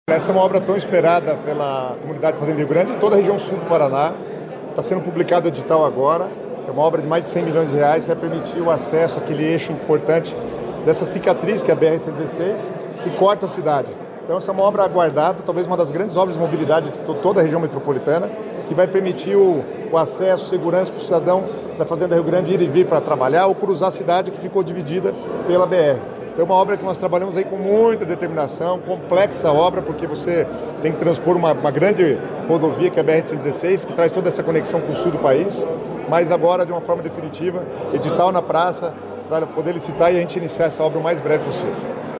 Sonora do secretário das Cidades, Guto Silva, sobre a publicação do aviso do edital de licitação para dois viadutos sobre a BR-116 em Fazenda Rio Grande